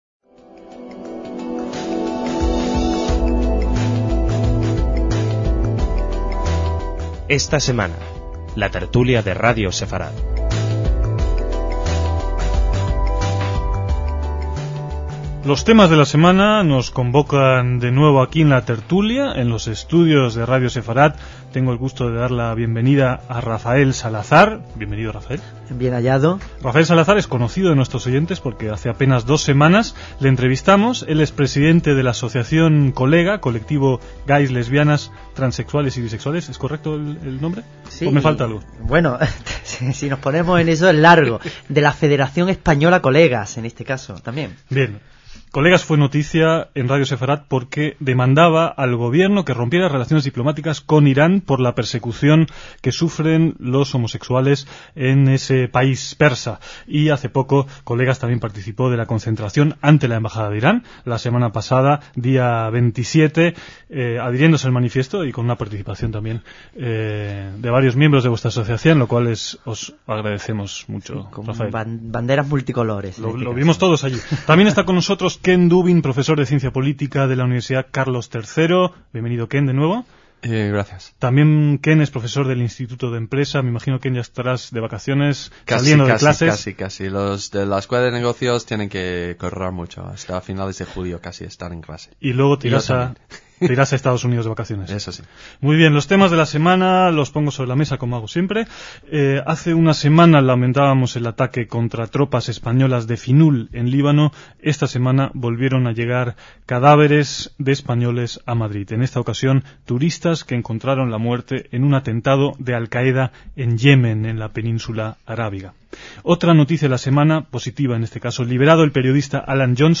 DECÍAMOS AYER (7/7/2007) - Dos temas son los que principalmente se trataron en esta tertulia: la homosexualidad en la sociedad española actual y la presencia del grupo terrorista Al Qaeda en Yemen.